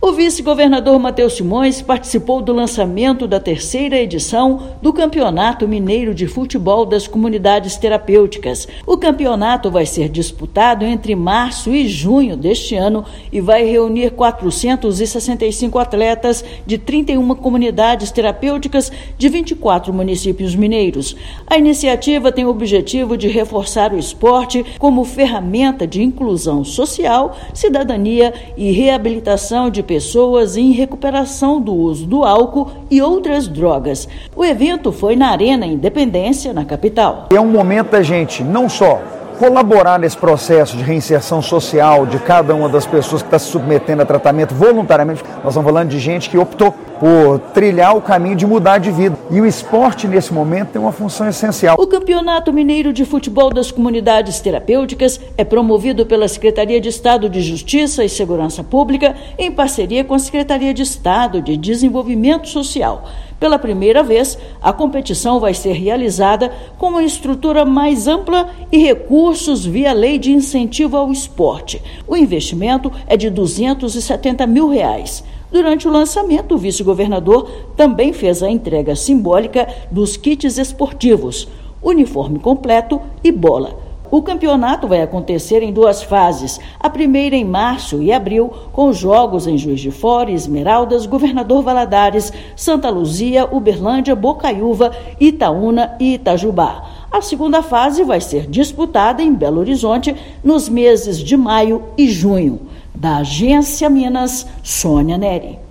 Competição ocorre entre março e junho, reunindo 465 atletas de 24 municípios mineiros em um evento que celebra o esporte como ferramenta de reconstrução de trajetórias. Ouça matéria de rádio.